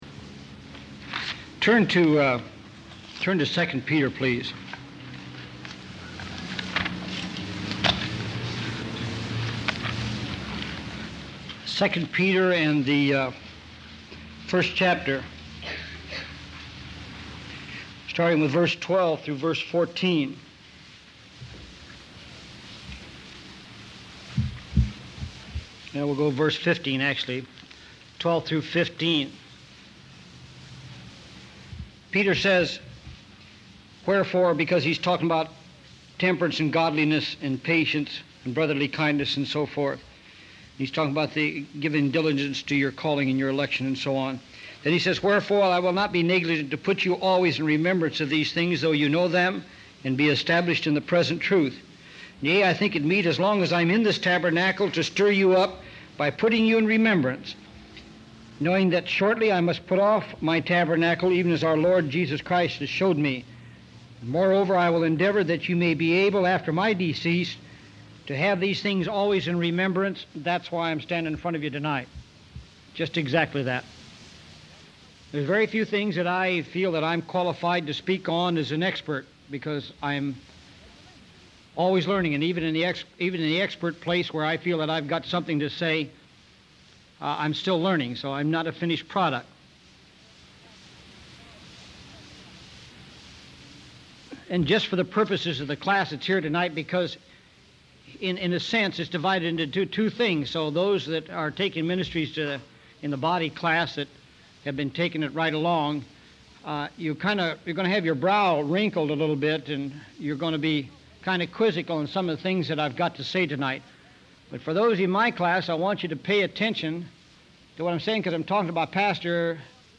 What is the Ministry download sermon mp3 download sermon notes Welcome to Calvary Chapel Knoxville!